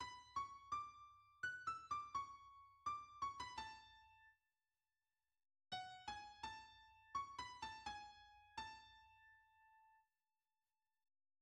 [a] Introduction: Andantino, 4/4, A major: